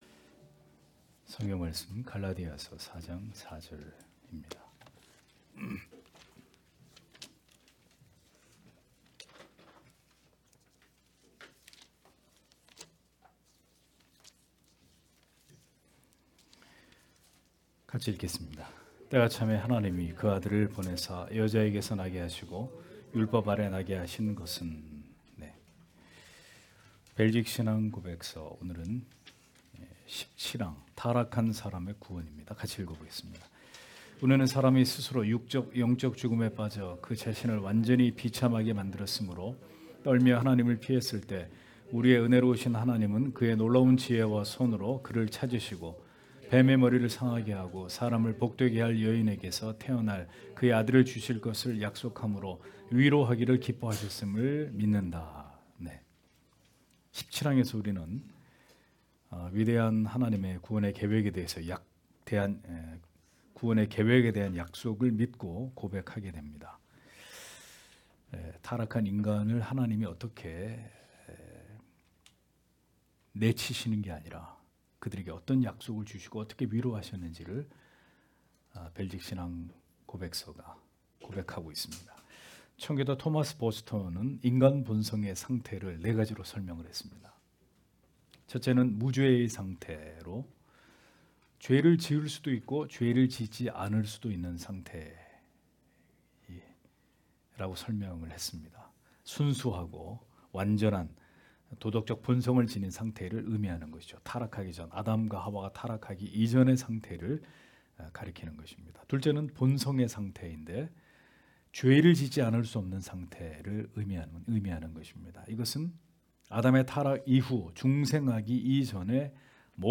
주일오후예배 - [벨직 신앙고백서 해설 18] 제17항 타락한 사람의 구원 (갈 4장 4절)
* 설교 파일을 다운 받으시려면 아래 설교 제목을 클릭해서 다운 받으시면 됩니다.